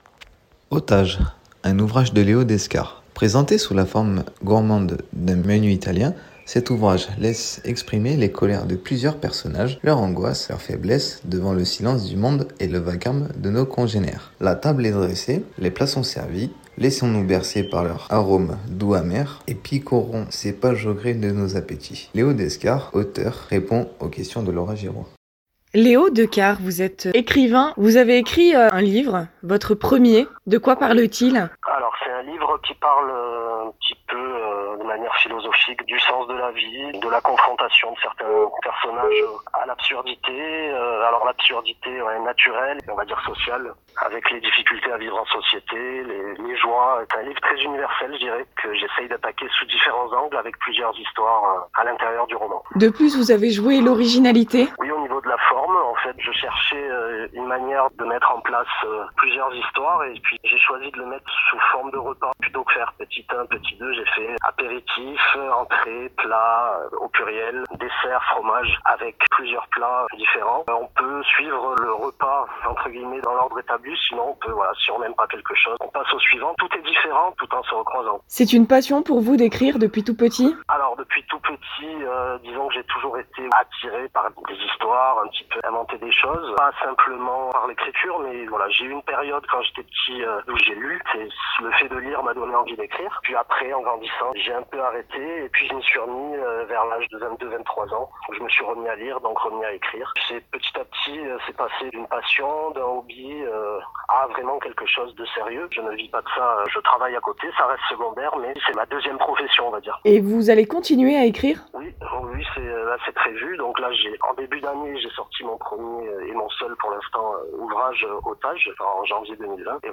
auteur répond aux questions de